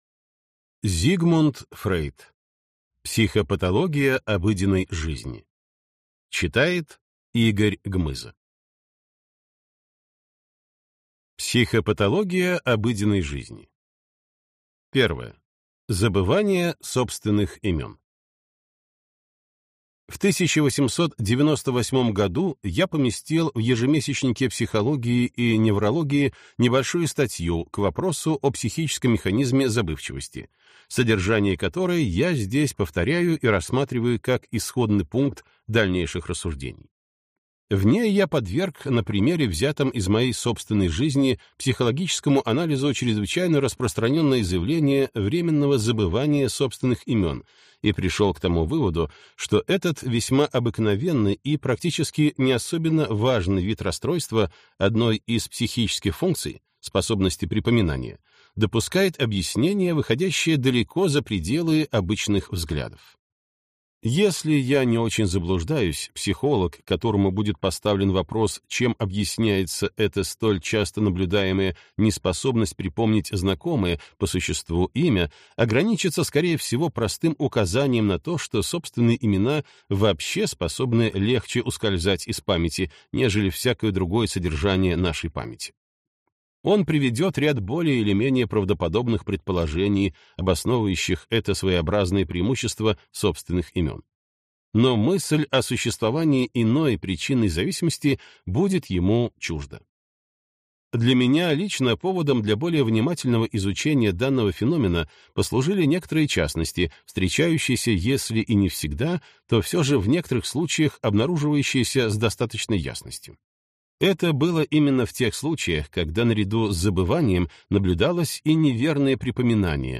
Аудиокнига Психопатология обыденной жизни | Библиотека аудиокниг